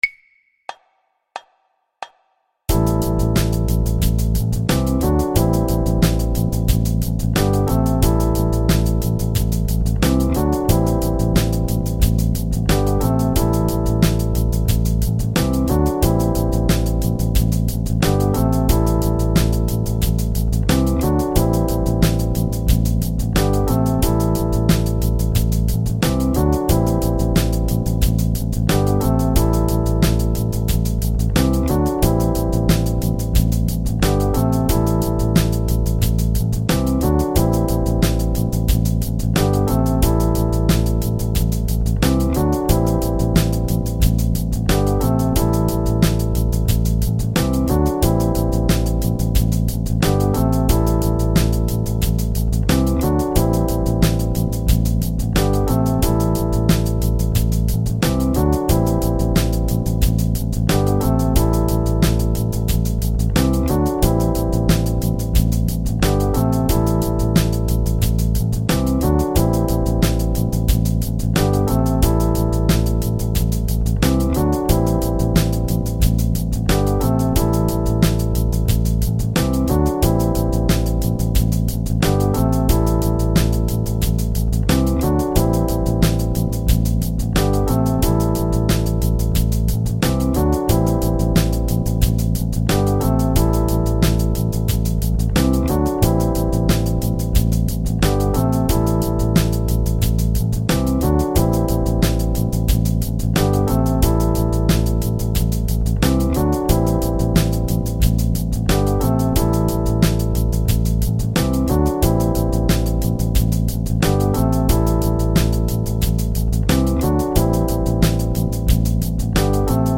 jam_rhythm_backing.mp3